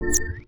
UIClick_Soft Dreamy Whistle Wobble 05.wav